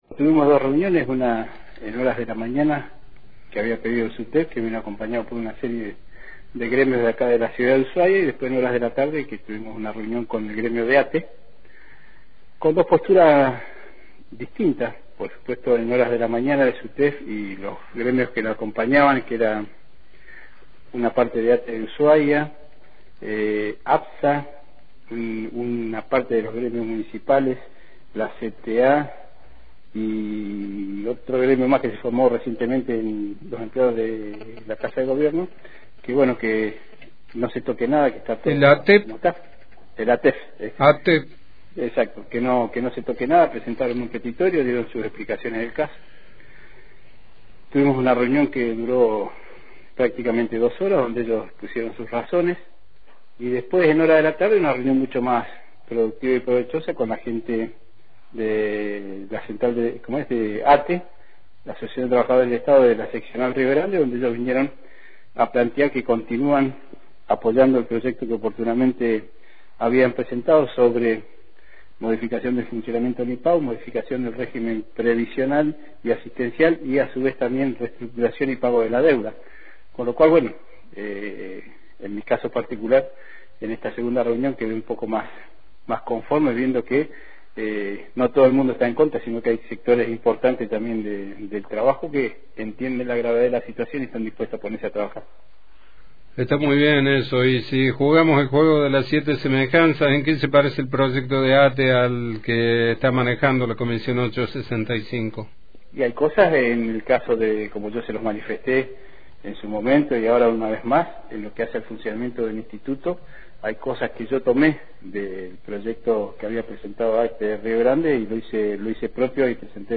En diálogo con RADIONOTICIAS, el legislador Pablo Daniel Blanco, presidente de la comisión ley 865 de análisis de la situación del Instituto, efectuó un repaso de lo actuado en las últimas horas sin poder disimular cierto cansancio por la falta de aportes constructivos.